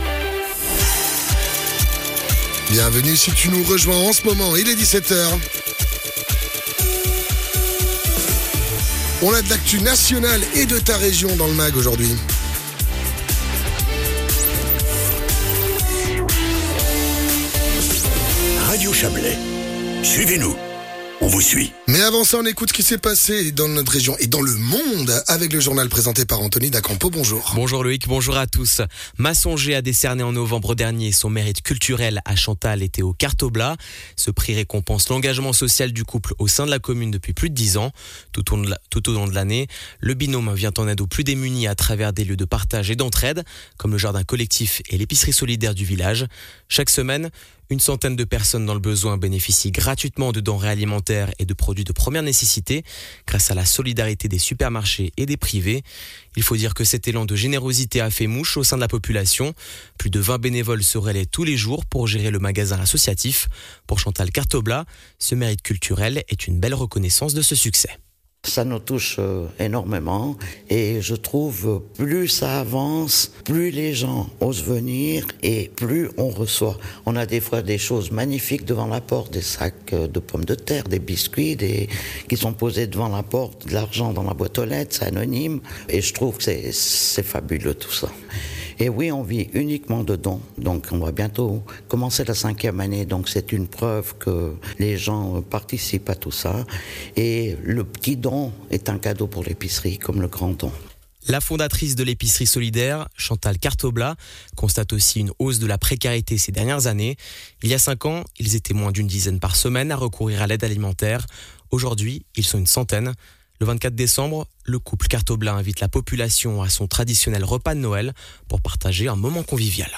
Les infos de 17h00 du 16.12.2024